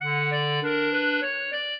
clarinet
minuet4-12.wav